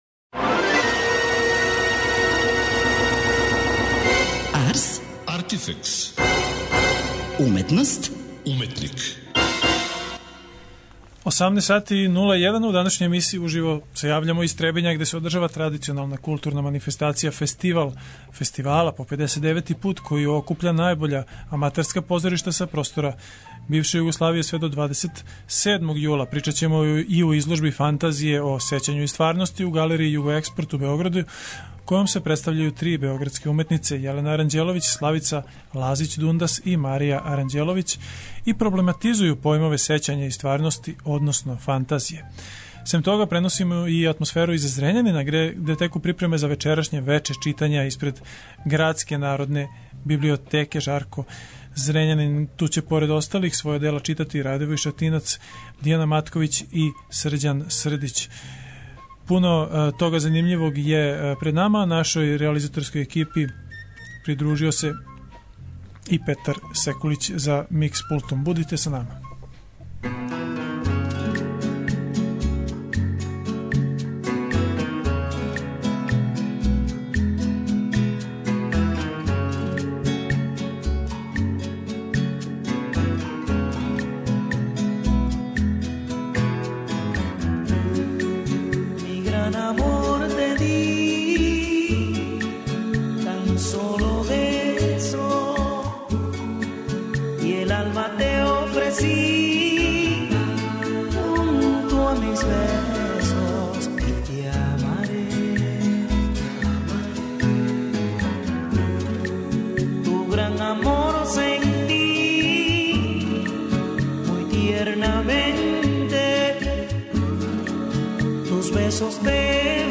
Уживо се јављамо из Требиња, где се одржава традиционална културна манифестација „Фестивал фестивала“ по 59. пут, која окупља најбоља аматерска позоришта са простора бивше Југославије, од 21. до 27. јула.
Преносимо атмосферу из Зрењанина где теку припреме за вечерашње вече читања испред Градске народне библиотеке.